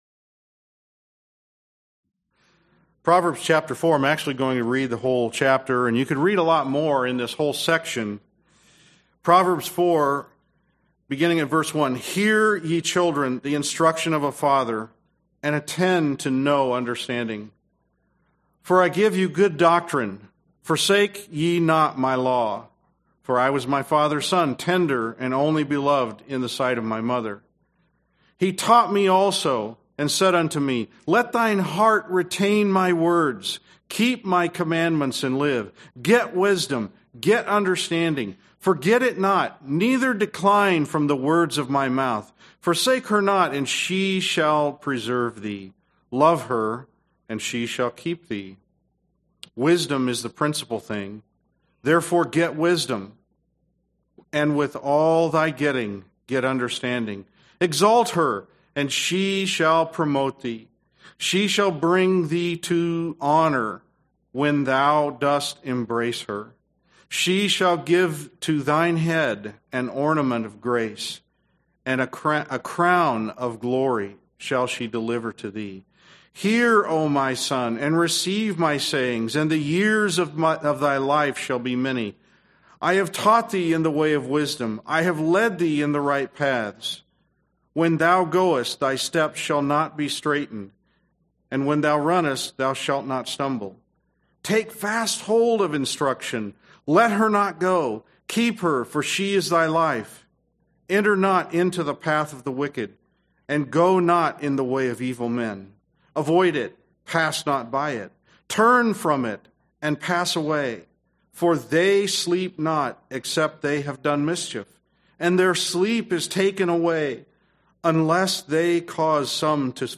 [SPEAKER] [SERMON DATE] 6/21/15 [SERIES] [SCRIPTURE REFERENCE] [SERMON ID] 1039